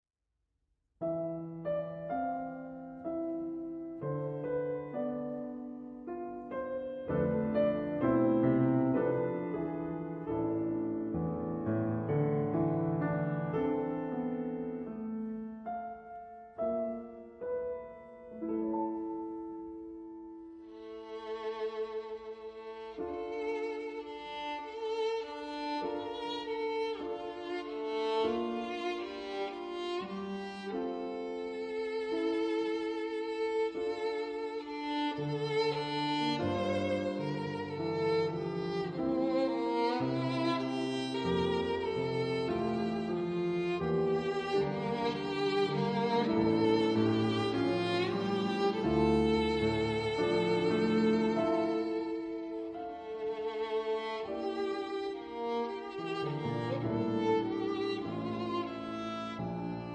Violine
Klavier
CD-Audio, stereo ·
Aufnahmeort: Franz-Liszt-Konzerthaus Raiding (Österreich)